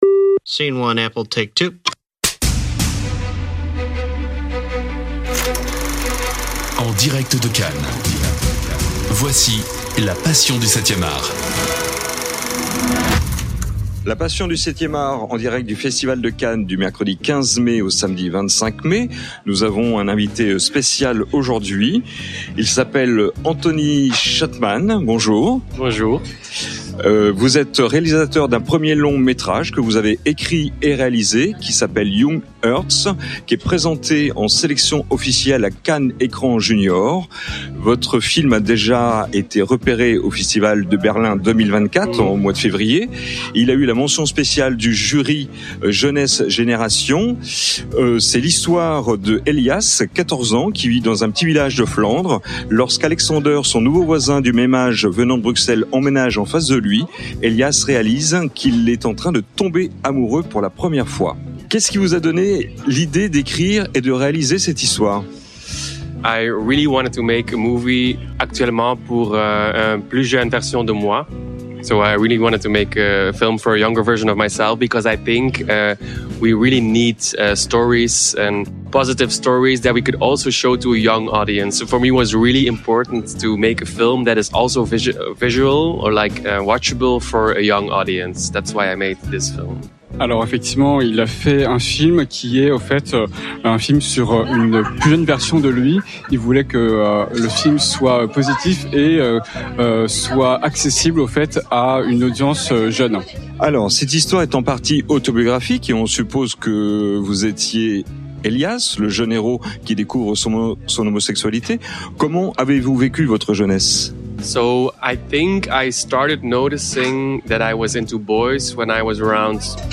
En direct de Cannes